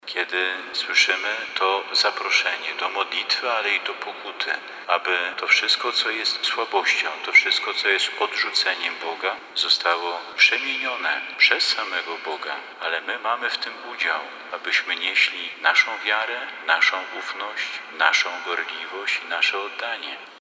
W kościele Najczystszego Serca Maryi przy placu Szembeka odbyło się przedwakacyjne spotkanie Grupy Ewangelizacyjnej Rodzinnych Kół Żywego Różańca, której członkowie jeżdżąc po parafiach diecezji warszawsko-praskiej dają świadectwo działania Boga w swoim życiu.
W homilii biskup Marek Solarczyk przypomniał, że iść za Chrystusem to wypełniać wolę Boga.